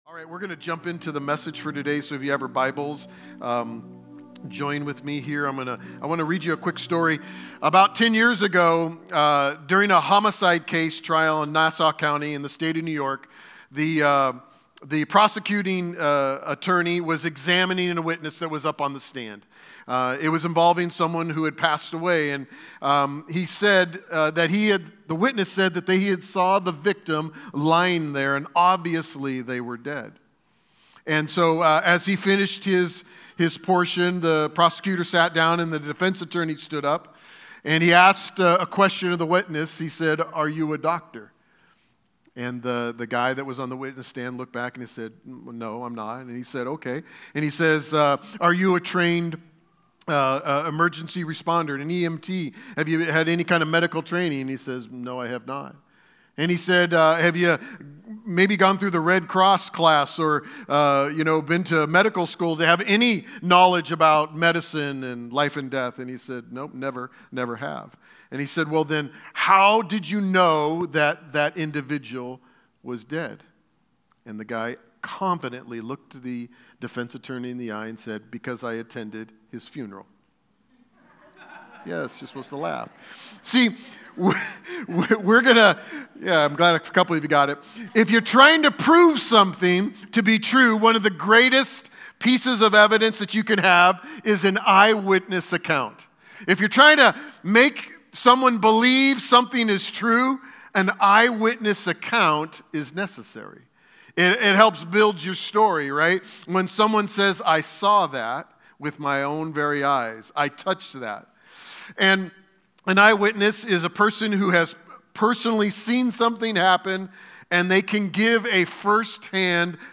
Sermons | Kingdom Church